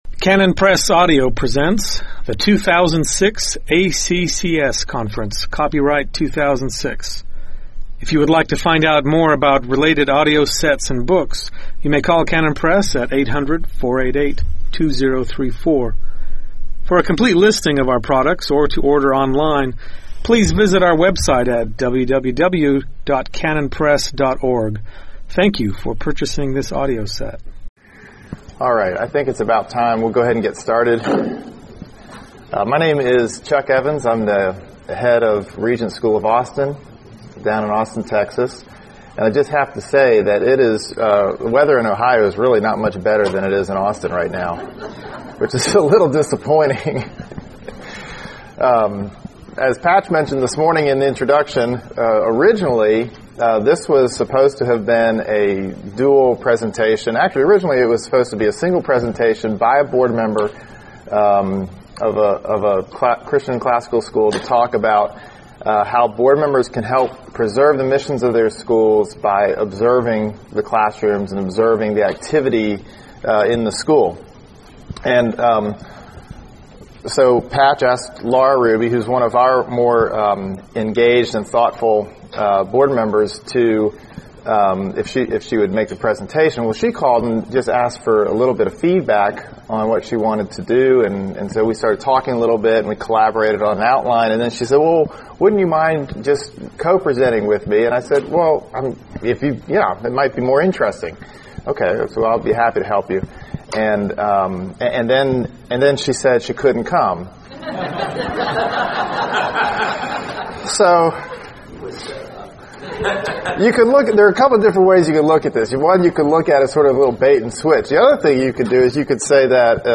2006 Workshop Talk | 0:47:05 | Leadership & Strategic, Training & Certification
Learn some ways board members and administrators can use classroom observations to work effectively in preserving their mission. Speaker Additional Materials The Association of Classical & Christian Schools presents Repairing the Ruins, the ACCS annual conference, copyright ACCS.